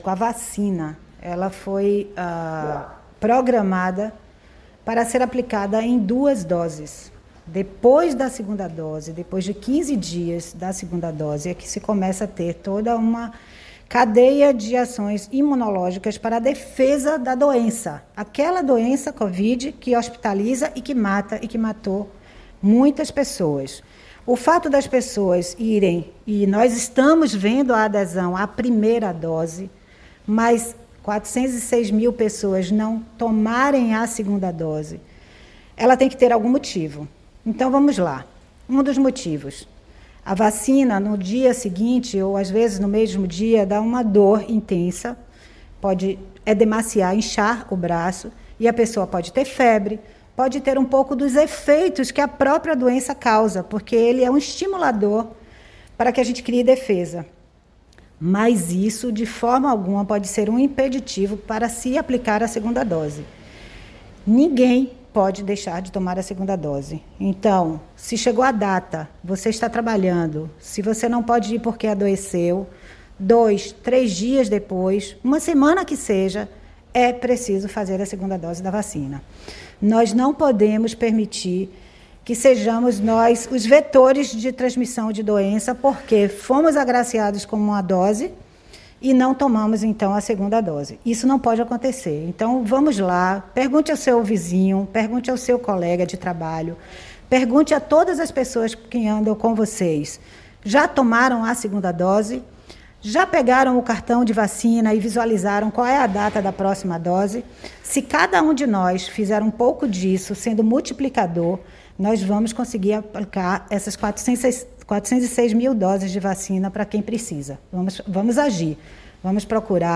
Nesta quinta-feira (5),  a secretária da saúde em exercício, Tereza Paim, reforçou a importância de se completar o esquema vacinal contra a infecção que já matou quase 26 mil baianos, desde o início da pandemia. Confiram a entrevista abaixo: